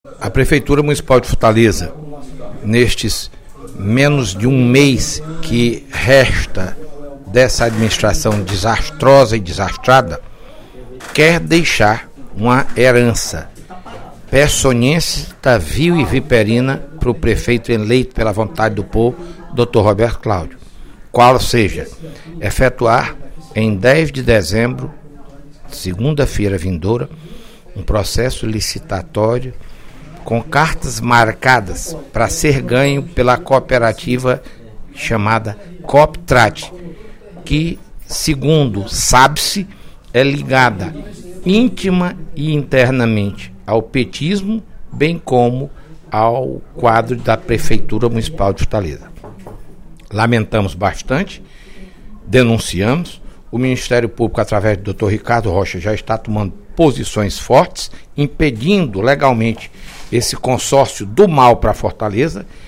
O deputado Fernando Hugo (PSDB) informou, no primeiro expediente da sessão plenária da Assembleia Legislativa desta quarta-feira (05/12), que apresentou requerimento solicitando à Polícia Civil investigar o destino de R$ 3,2 milhões arrecadados pelo Sindicato dos Permissionários do Transporte Alternativo de Fortaleza (Sindivans).